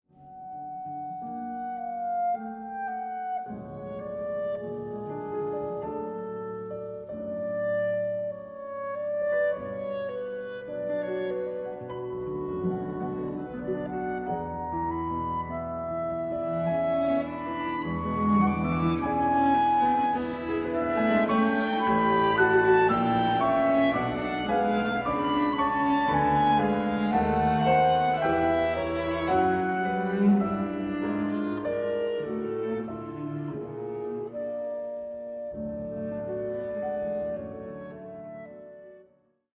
Chamber Music